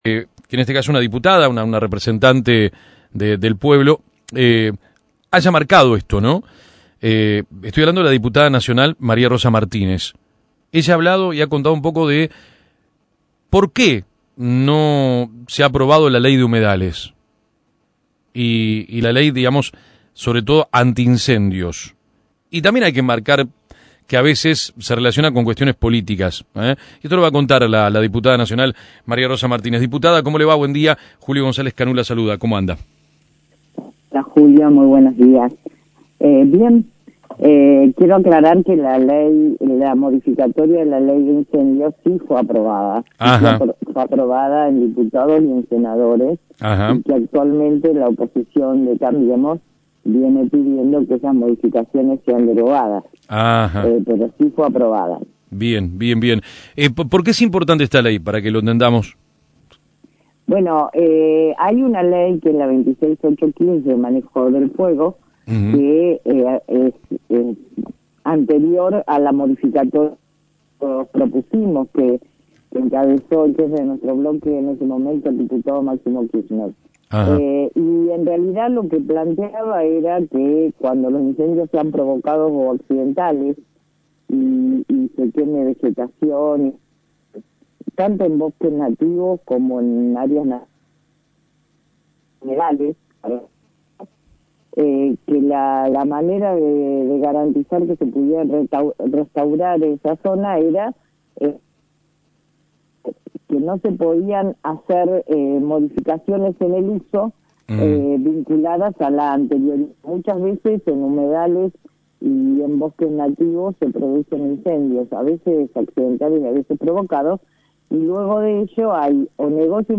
Humedales e incendios:La diputada nacional María Rosa Martínez habló en AM 1210 - LasFloresDigital